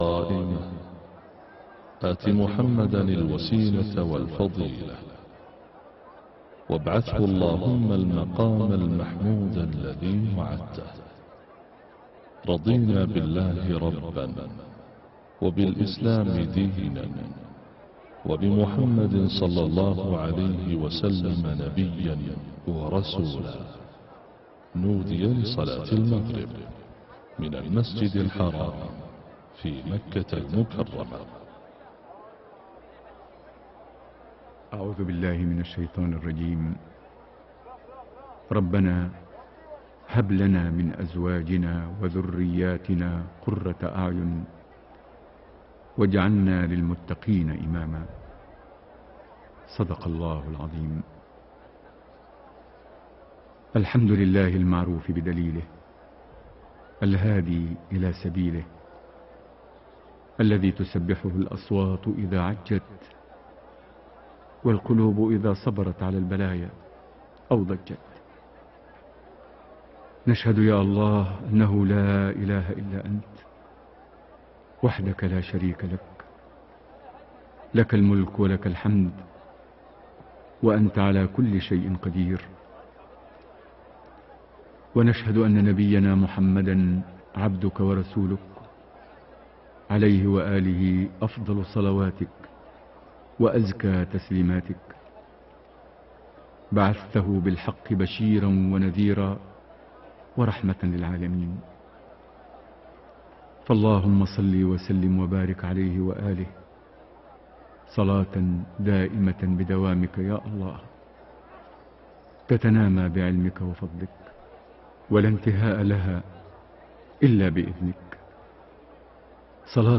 صلاة المغرب 16 ذو الحجة 1432هـ خواتيم سورة البقرة 284-286 > 1432 هـ > الفروض - تلاوات ماهر المعيقلي